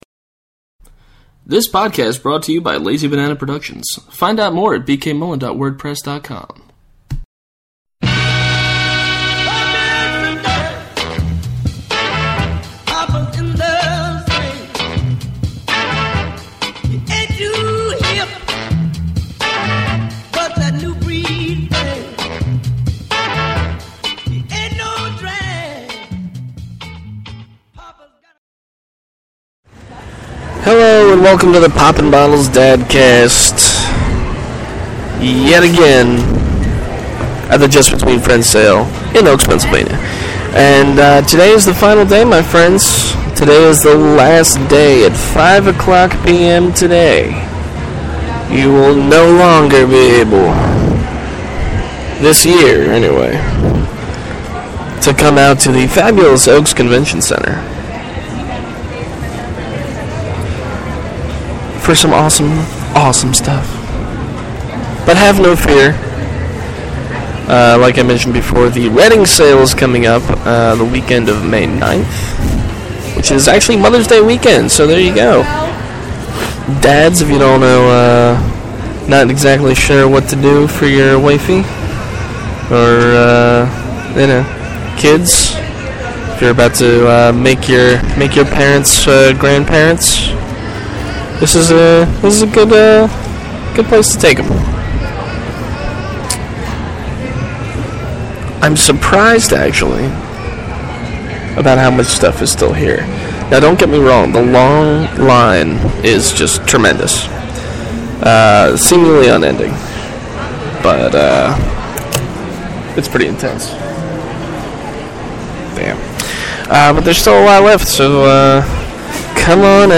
E29: Live from JBF Finale'!